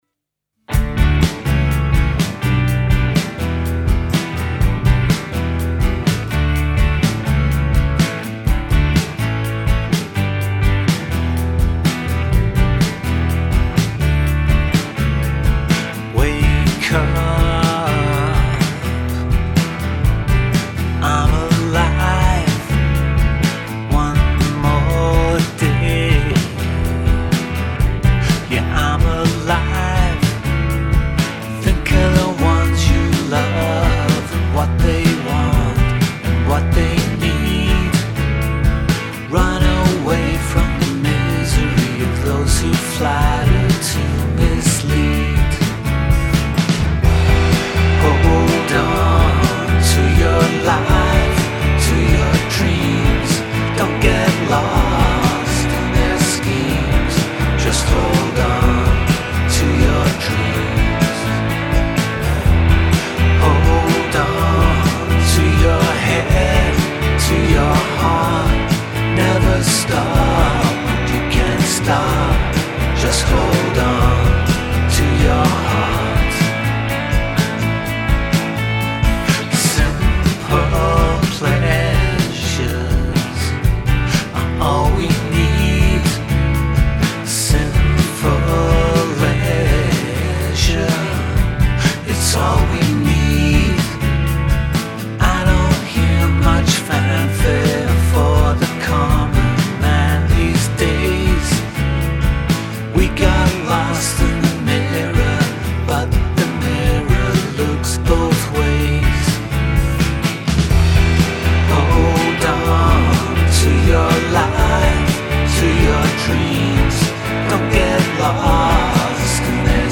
Scottish alternative outfit
whimsical dream rock
comfortably warm and soft-sung nature